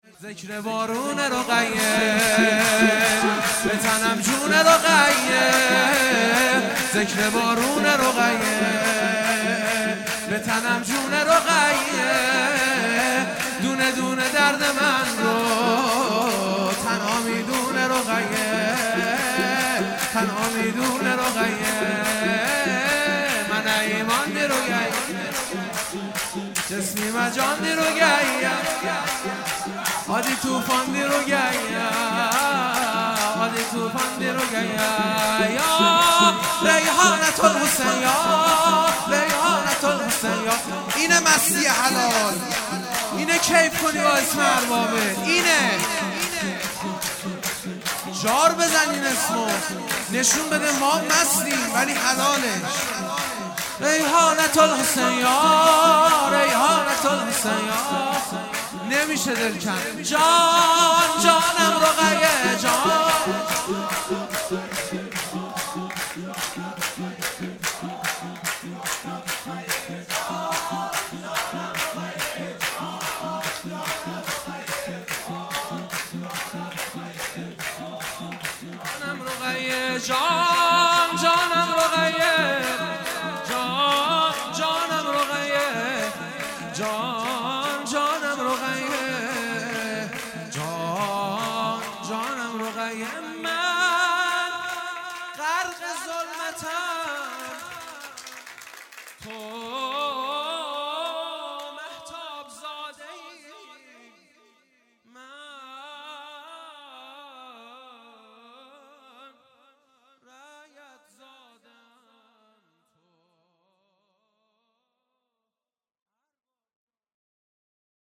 سرود
ولادت حضرت رقیه (س)